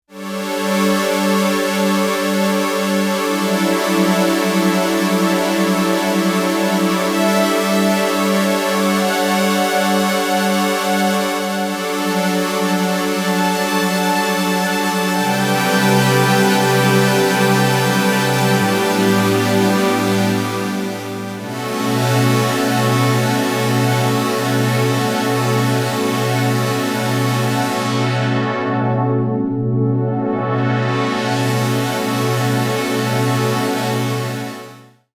暖かさと程よい粒の粗さが雰囲気を醸し出しています。